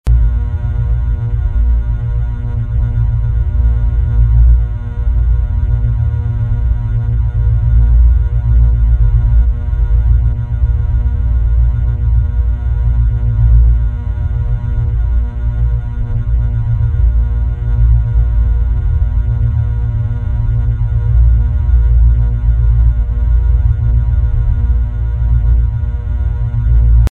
Index of /files/preload/sound/ambience/
forcefield1loop.mp3